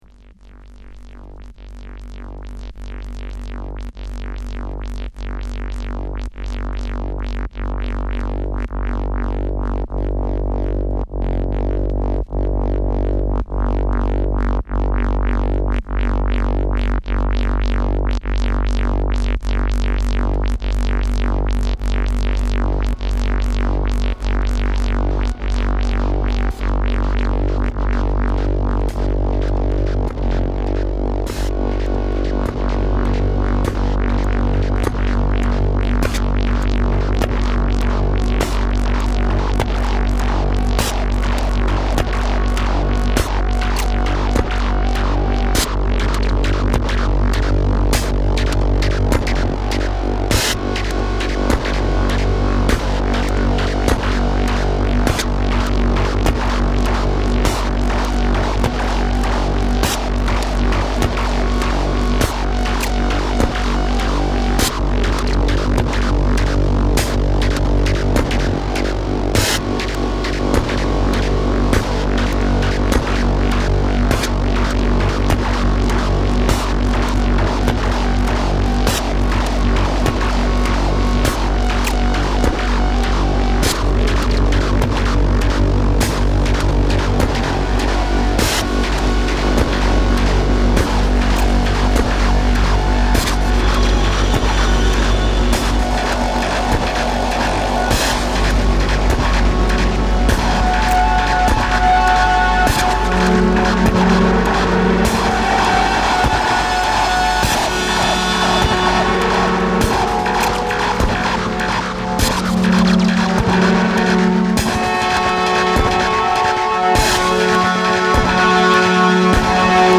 LP]リズミック・ノイズテクノミニマル